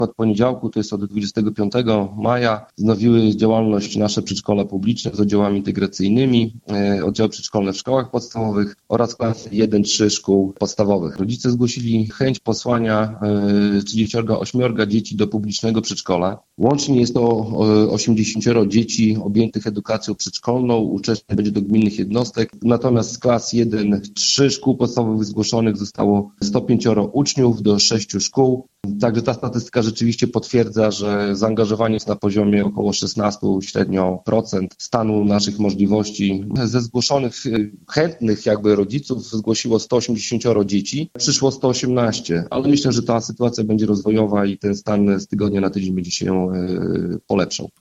O aktualnej sytuacji w mieście rozmawialiśmy z Karolem Sobczakiem, burmistrzem Olecka.